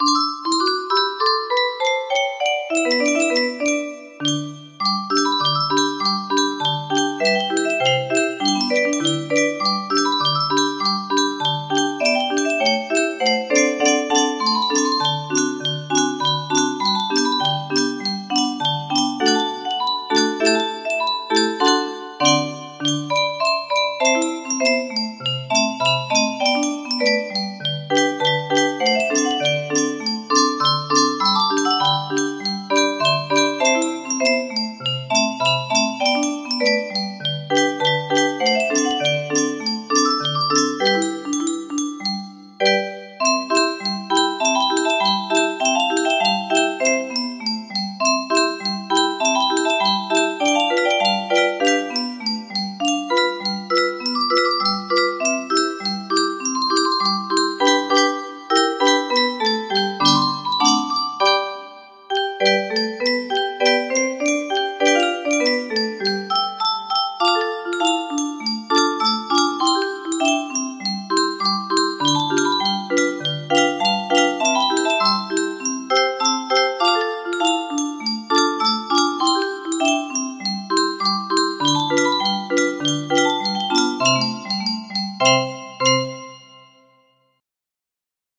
Fernando Pichardo - Siluetas music box melody
Cakewalk de Fernando Pichardo, publicado en México en 1905 por A. Wagner y Levien